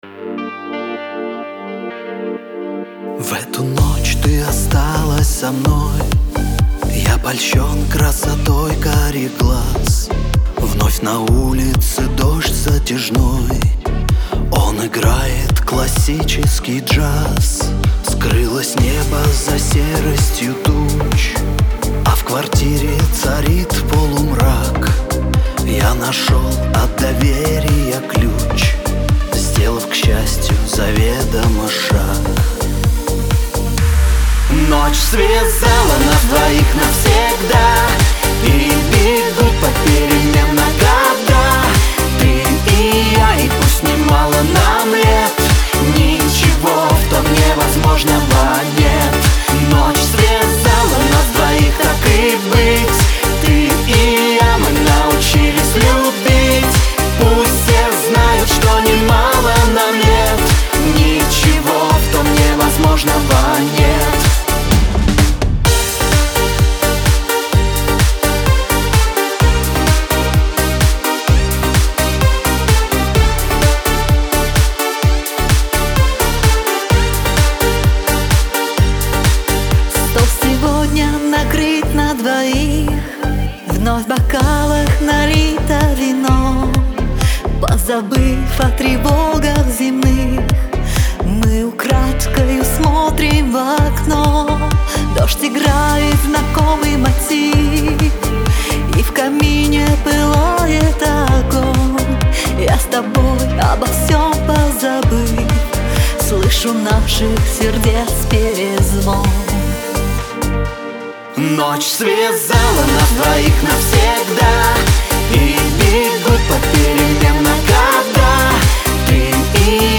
Лирика , дуэт
диско